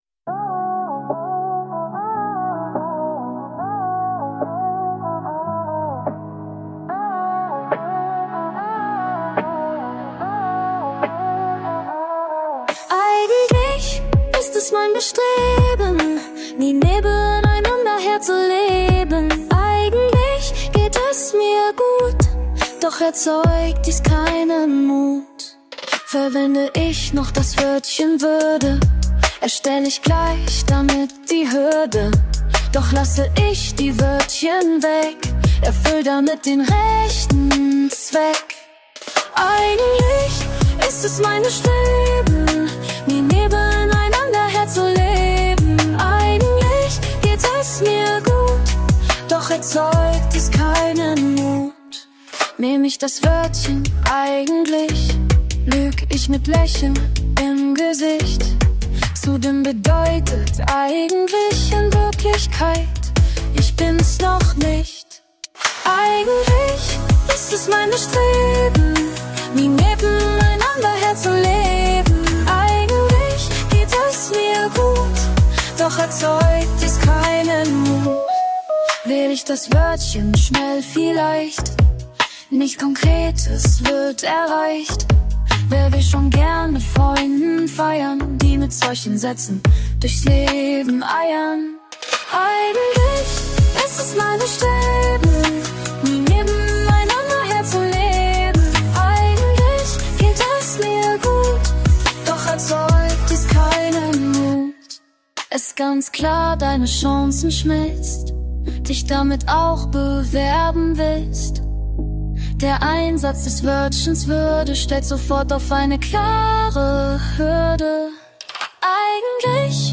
Eigentlich-haette-Neo-Soul.mp3